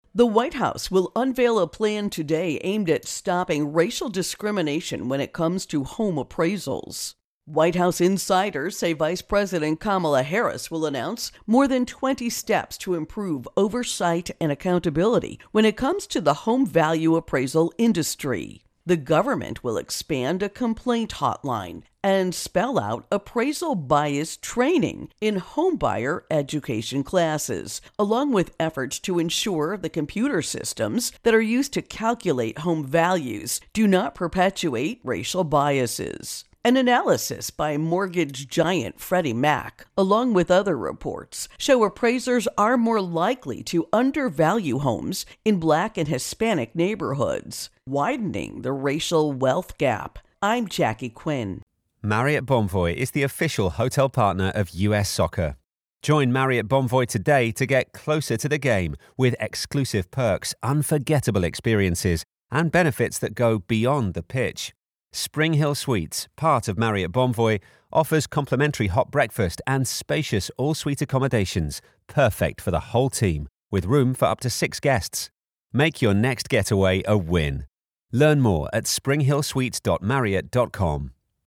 Biden Home Appraisals Intro and Voicer